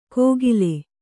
♪ kōgile